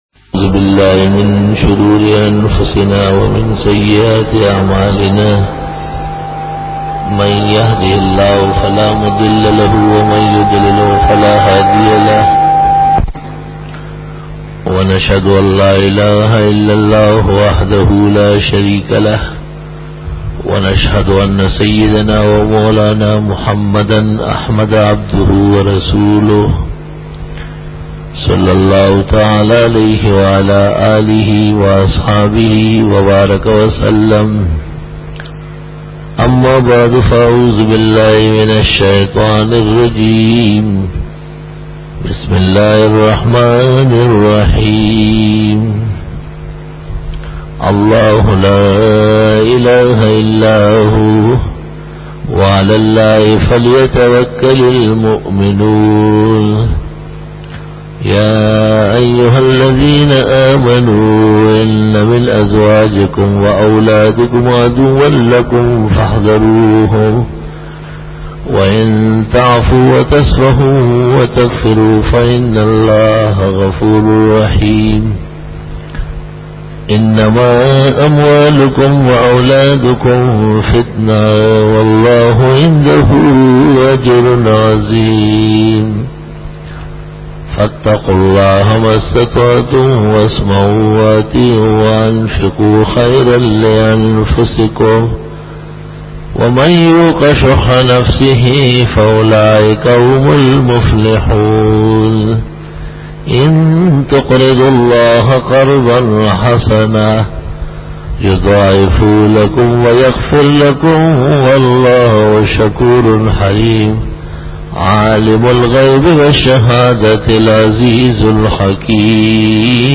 035_Jummah_Bayan_25_Oct_2002
بیان جمعۃ المبارک 25 اکتوبر 2002